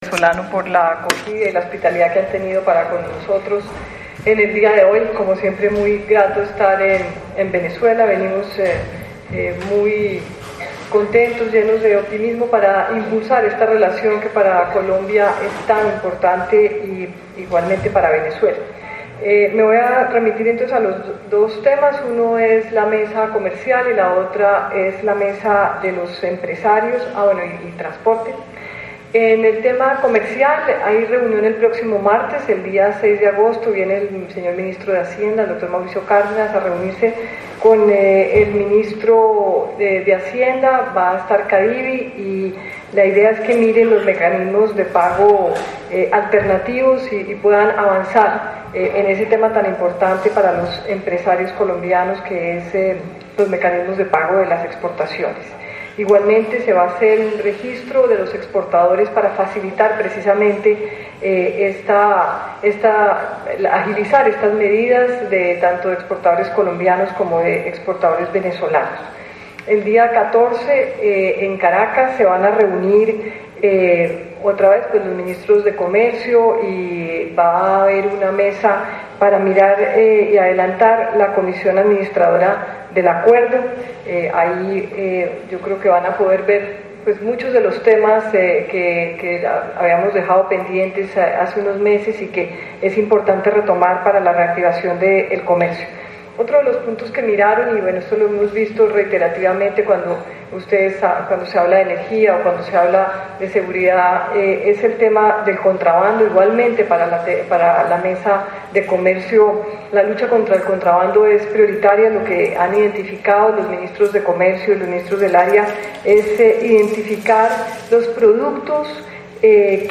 Canciller María Ángela Holguín presenta un balance de la Comisión Mixta Binacional Colombia – Cancillería